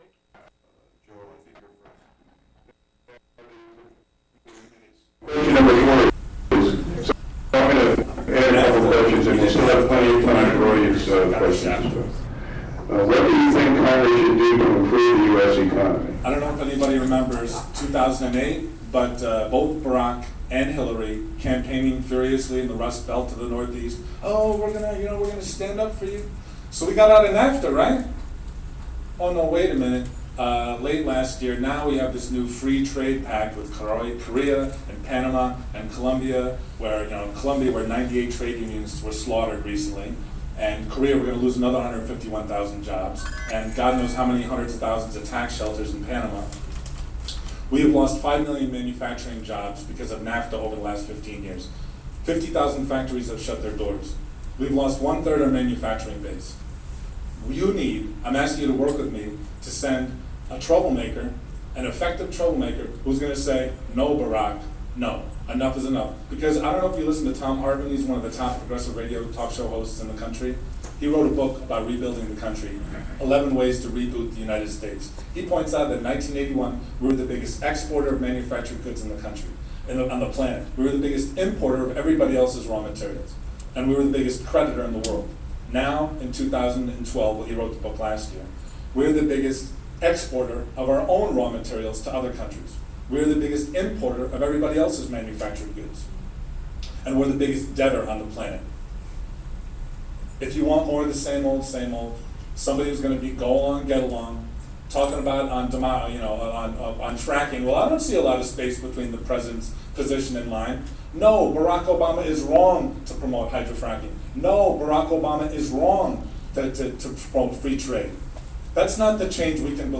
Live Congressional Debate
Recorded from The Daily Freeman livestream on WGXC 90.7-FM.